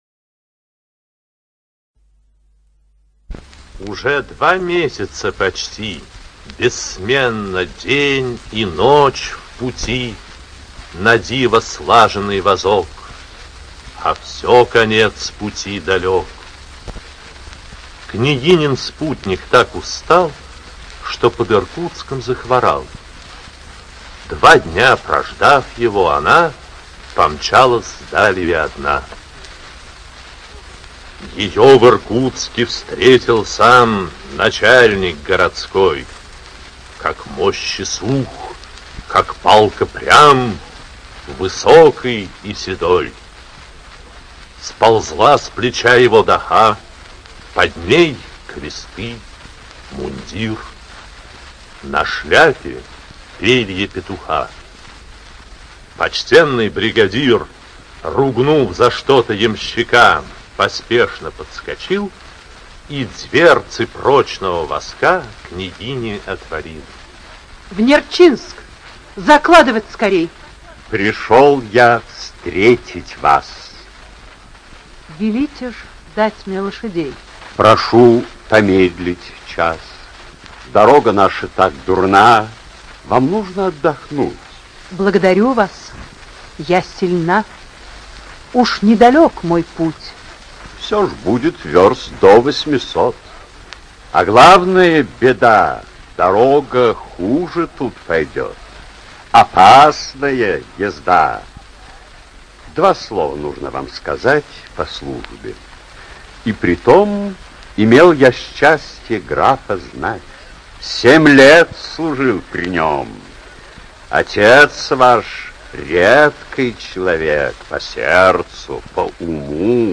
ЧитаютКачалов В., Массальский П., Тарасова А., Вербицкий В.
ЖанрПоэзия